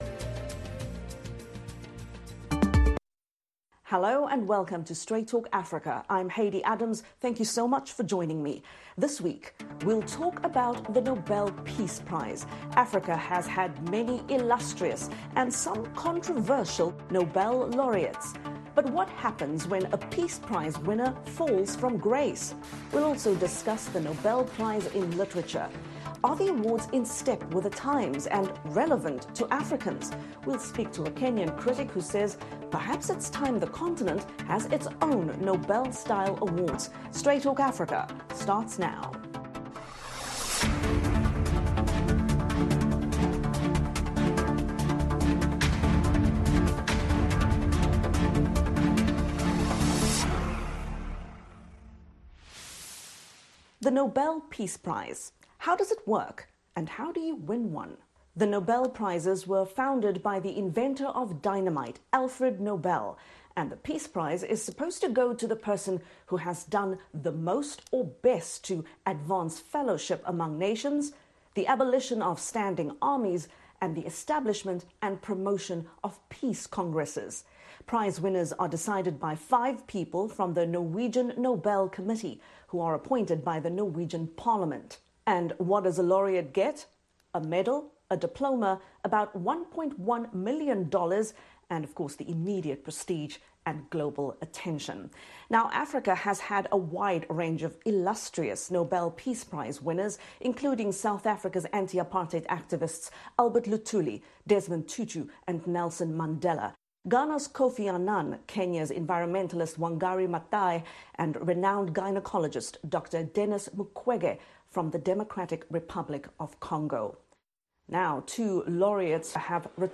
The Relevance of the Nobel Prize [simulcast]